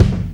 kick 4.wav